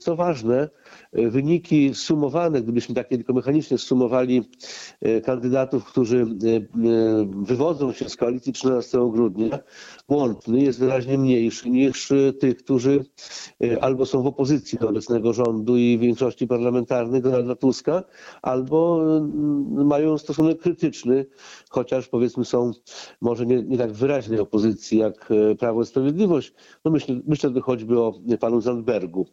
– Wszystko się może zdarzyć, Polska jest przepołowiona – komentował wynik I tury wyborów prezydenckich Jarosław Zieliński, poseł Prawa i Sprawiedliwości z Suwałk. Podczas rozmowy z Radiem 5 zwrócił uwagę, że mieszkańcy Polski wschodniej głosowali na Karola Nawrockiego, kandydata popieranego przez PiS.